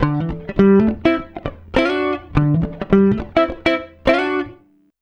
104FUNKY 12.wav